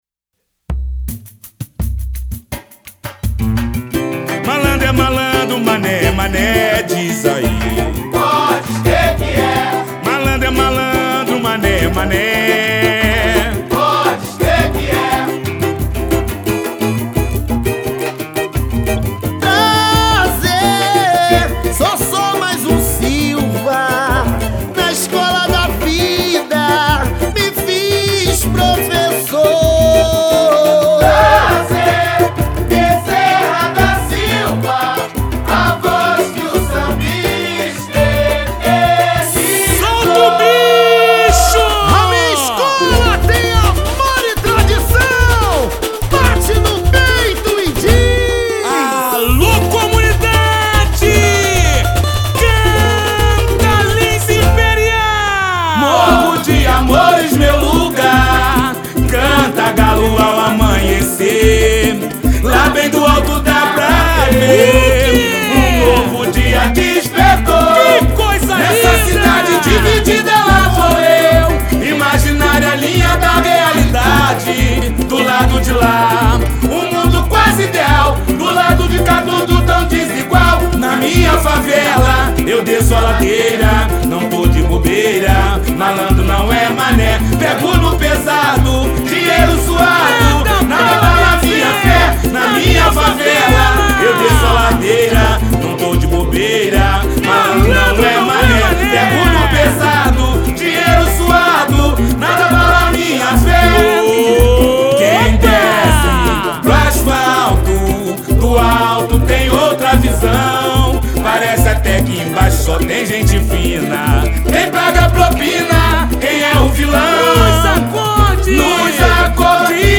Samba 7